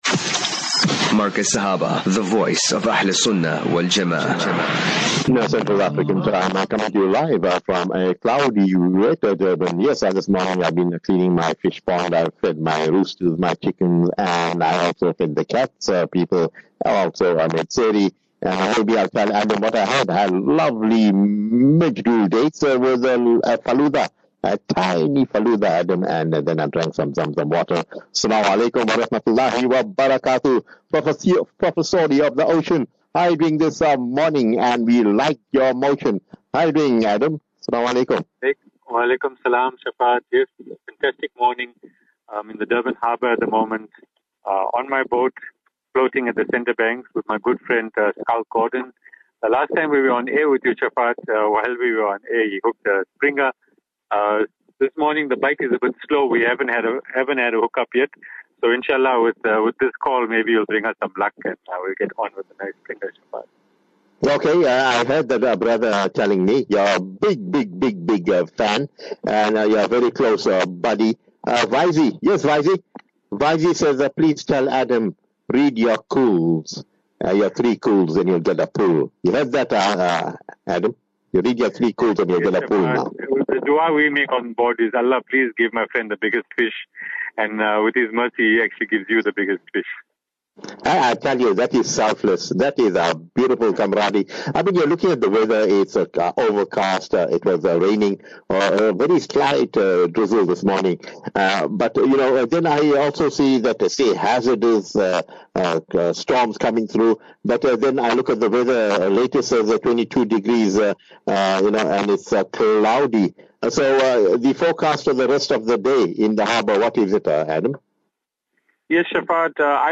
live from The Durban Harbour..